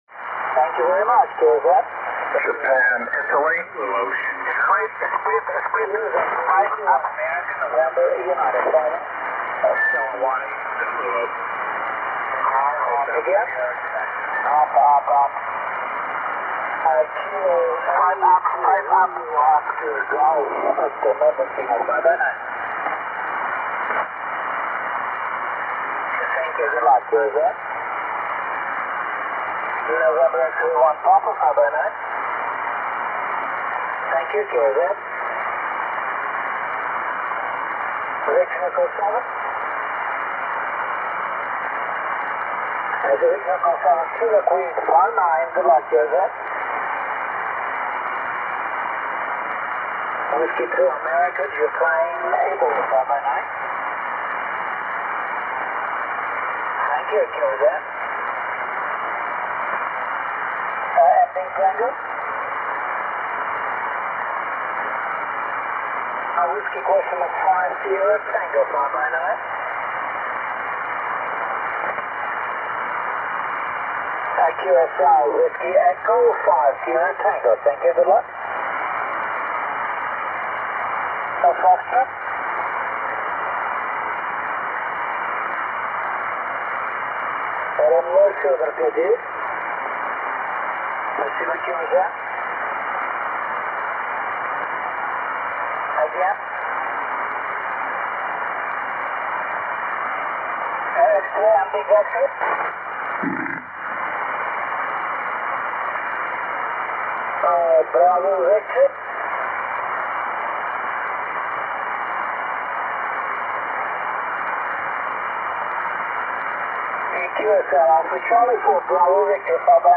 RI1ANU on 20m USB - South Shetland Is. (AN-010) Date: 2013-11-21 Time: 02:40 UTC